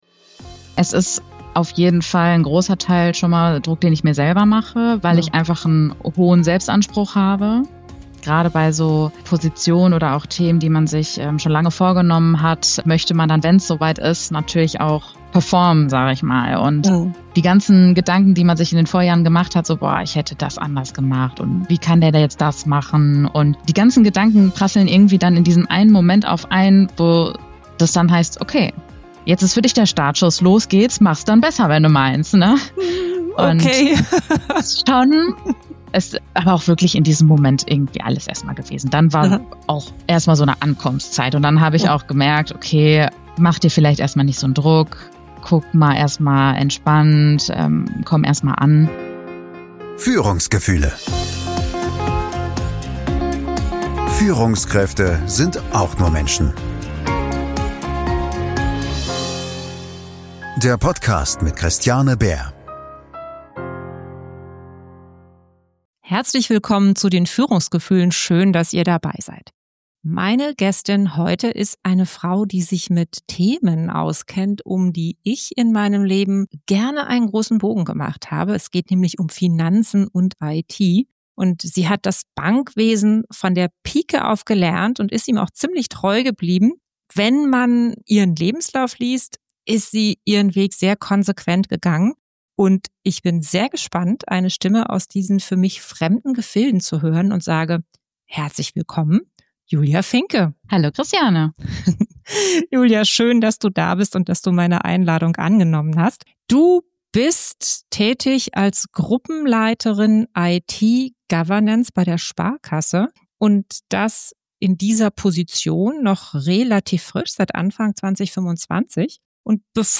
Diese Folge ist ein ehrliches, inspirierendes Gespräch mit einer jungen Frau, die Führung nicht als fertige Rolle versteht, sondern als Prozess.